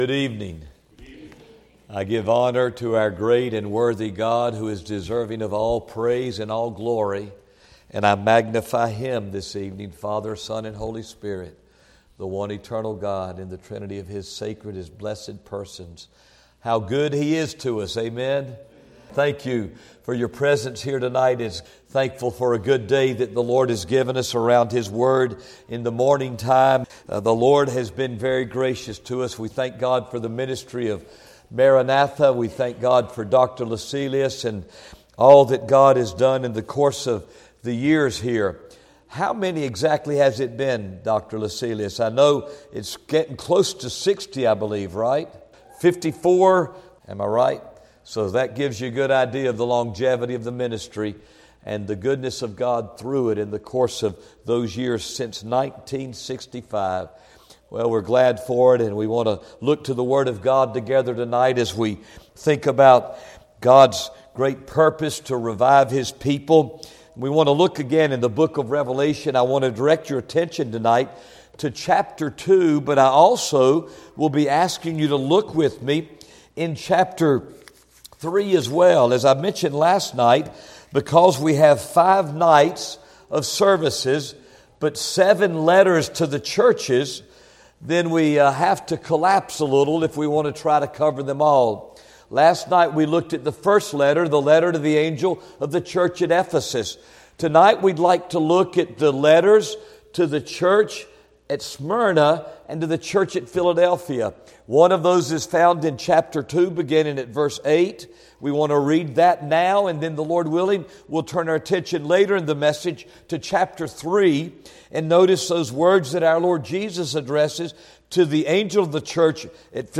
Series: 2019 July Conference Session: Evening Session